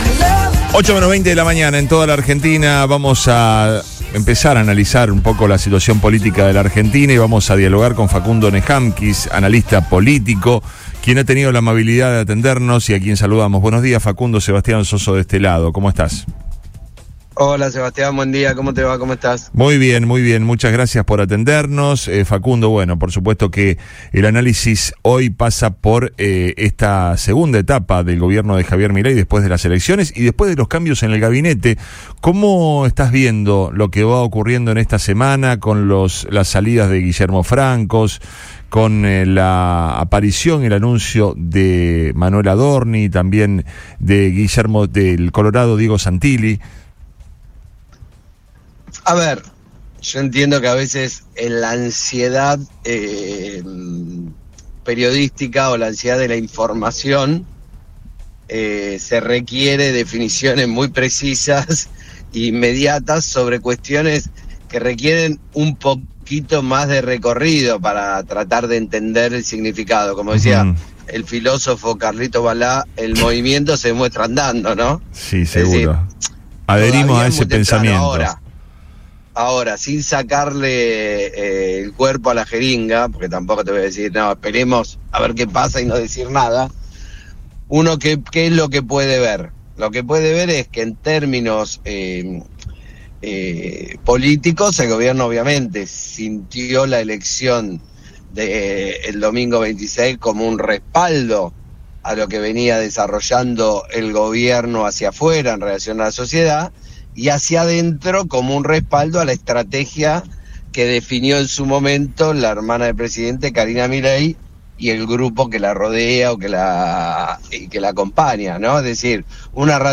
dialogó en Río Extra Primera Mañana por Río 96.9, donde ofreció un análisis sobre el escenario político argentino tras las elecciones y los recientes cambios en el gabinete del presidente Javier Milei.